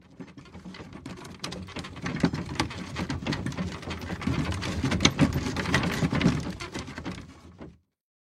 Tiếng Bánh Xe đẩy lăn lạch cạch… từ xa đến gần
Thể loại: Tiếng xe cộ
Description: Tiếng bánh xe đẩy lăn lộc cộc, lạch cạch vang vọng trong màn đêm tĩnh mịch. Từ xa, âm thanh khe khẽ như nhịp thở của phố cũ, dần rõ ràng, vang dội giữa con ngõ hẹp. Tiếng kẽo kẹt của xe gỗ, tiếng lọc cọc của trục bánh. Âm thanh ấy mộc mạc, gợi nhớ một thời xưa cũ – xe ngựa, xe thồ chở hàng qua phố, vang vọng nhịp sống cũ kỹ, khắc khoải, ngân nga trong không gian hoài niệm.
tieng-banh-xe-day-lan-lach-cach-tu-xa-den-gan-www_tiengdong_com.mp3